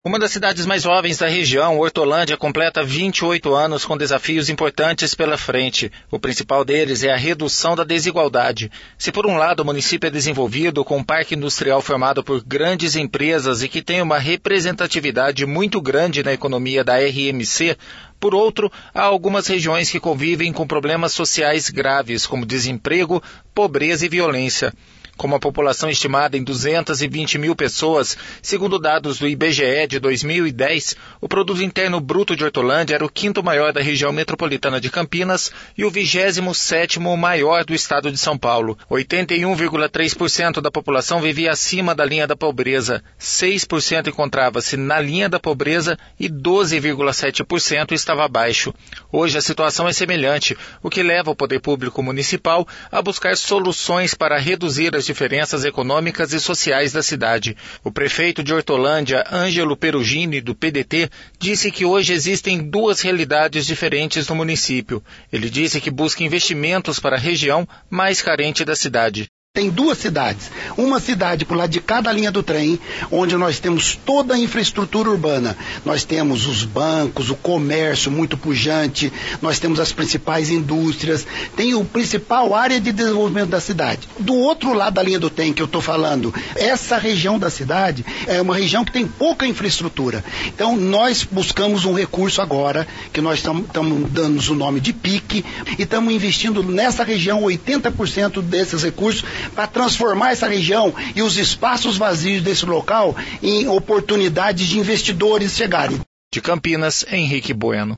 O prefeito de Hortolândia, Ângelo Peruggini, do PDT, disse que hoje existem duas realidades diferentes no município. Ele disse que busca investimentos para a região mais carente do município.